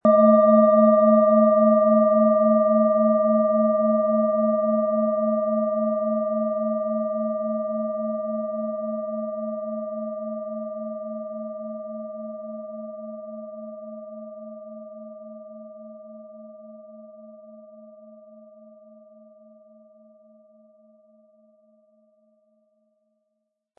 Antike Klangschalen – Schätze der Achtsamkeit
Ihr Klang ist weich, rund und wohltuend – er breitet sich sanft aus und schenkt dir ein Gefühl von Geborgenheit, Zentrierung und innerem Gleichgewicht.
Ihr Klang wirkt wie eine liebevolle Umarmung: beruhigend, einladend und tief entspannend.
Lauschen Sie in Ruhe hinein und lassen Sie die tiefe, tragende Schwingung des Biorythmuse-Seele-Tons auf sich wirken – klar, zentrierend und kraftvoll.
Er bringt den vollen, harmonischen Ton der Biorythmus-Seele-Frequenz optimal zur Geltung.
MaterialBronze